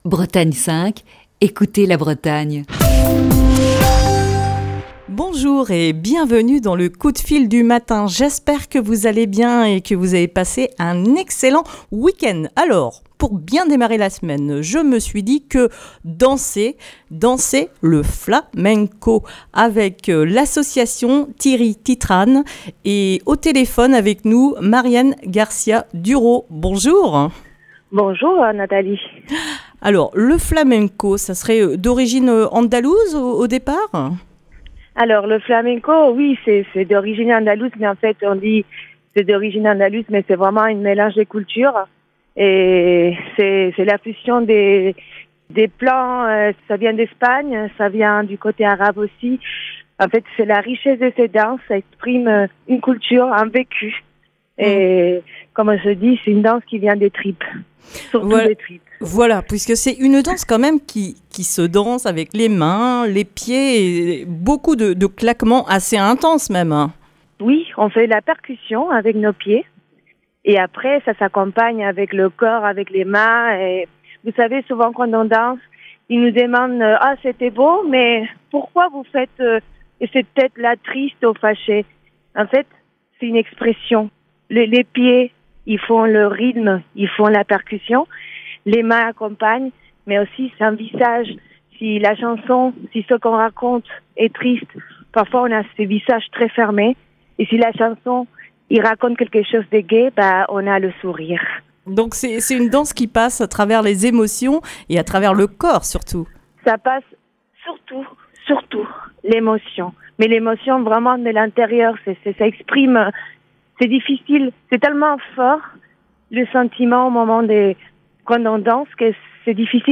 Ce lundi, dans le coup de fil du matin, nous parlons de flamenco et de danse.